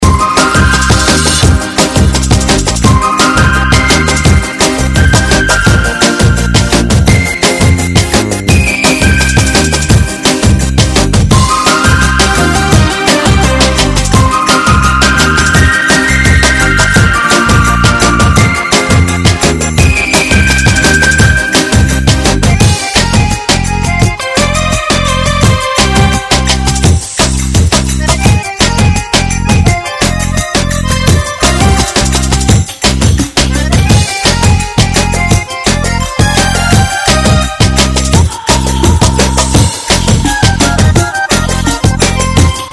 инструментальные
фолк-музыка